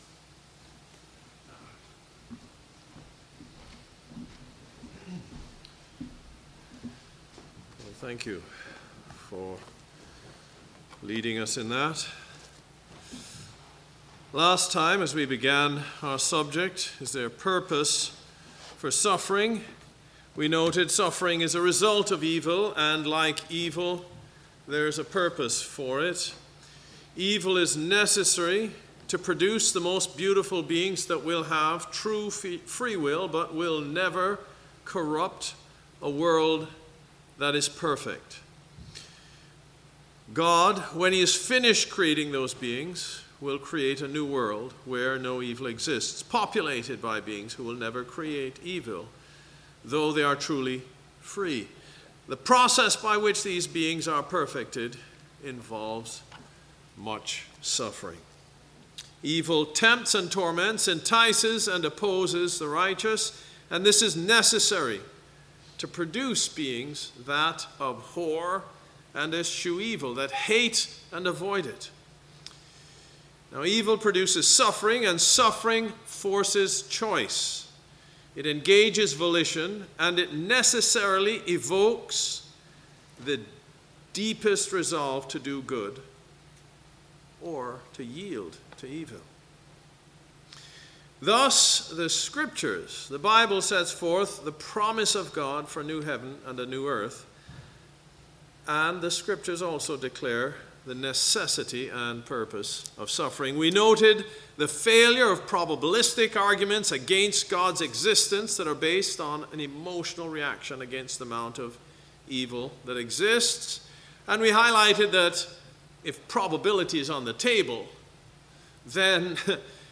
Sermons – The Church At Vienna
From Series: "Sunday Morning - 10:30"